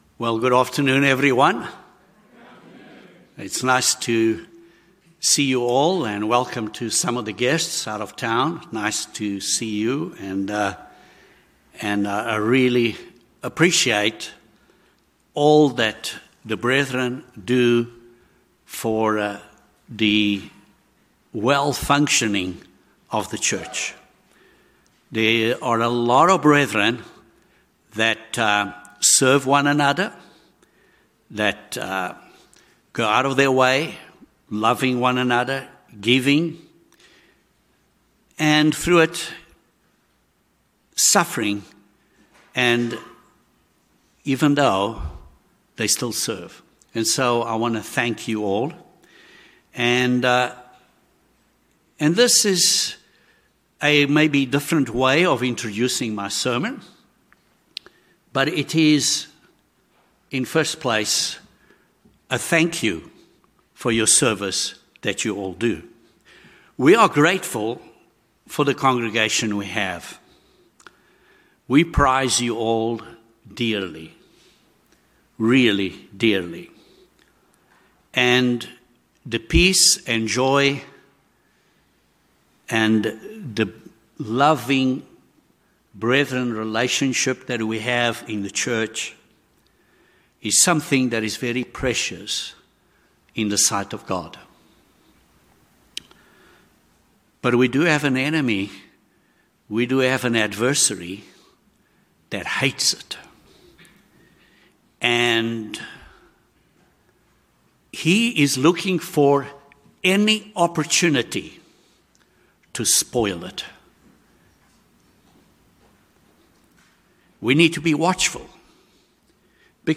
In this sermon we will extract a few points of advice of things to do from Paul's first message to the Thessalonians, so we are not caught unawares.